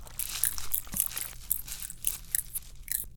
Spider_walk.ogg